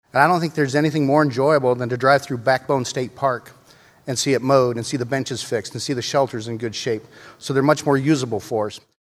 Senator Dan Zumbach, a Republican from Ryan, says he met with the DNR’s director to shift money within the agency’s budget, to focus on priorities.